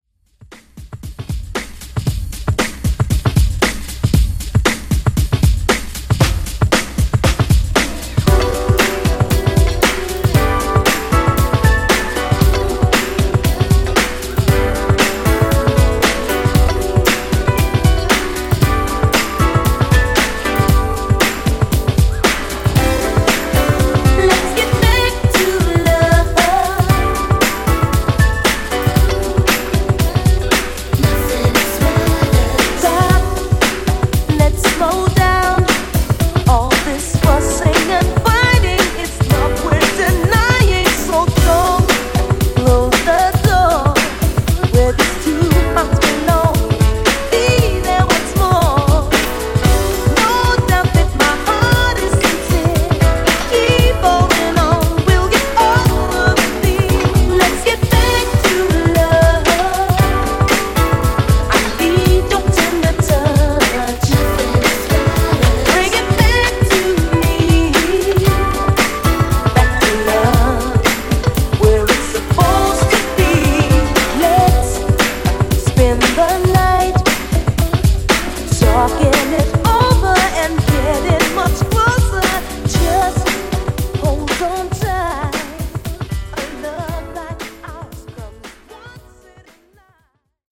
80s Redrum)Date Added